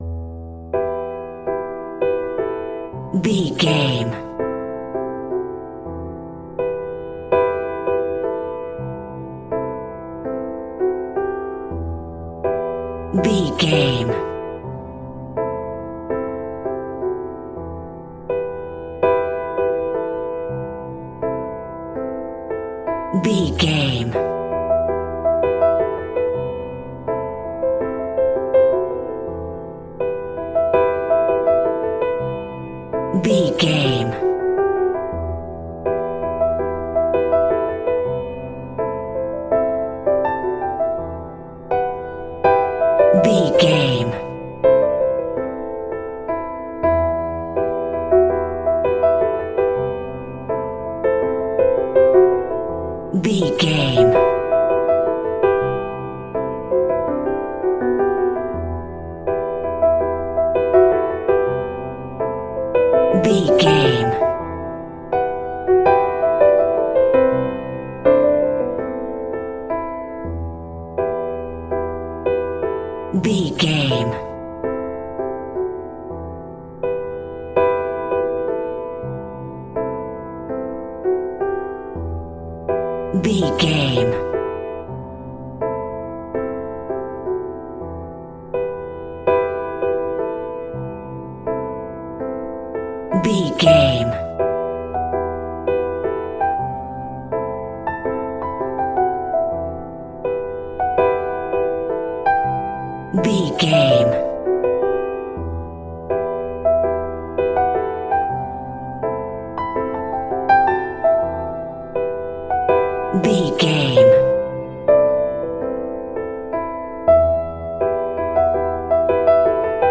Light and chilled ambient piano melodies in a major key.
Regal and romantic, a classy piece of classical music.
romantic
soft